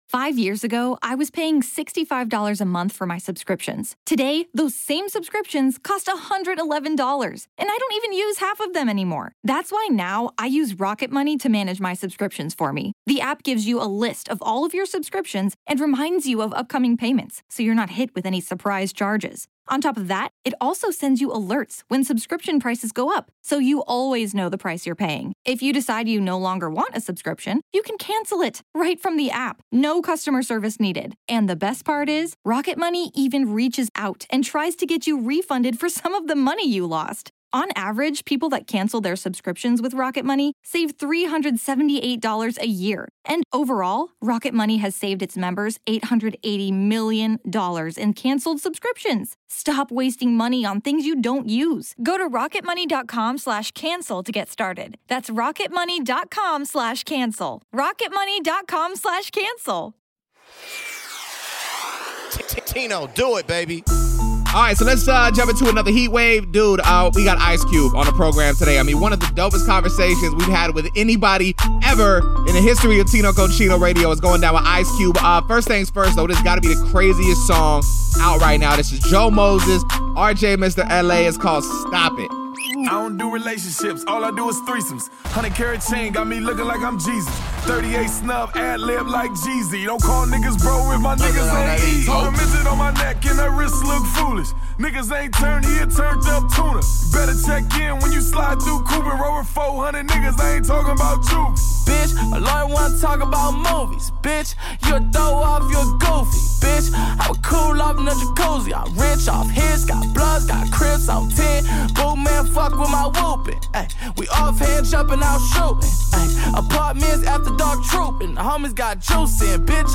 One of the dopest interviews we've ever had! The living legend ICE CUBE dropped by TCR to talk about Dr. Dre, The Backstreet Boys, an off day, and more! Plus, you know we're playing the latest, greatest, pretty much whatever we want!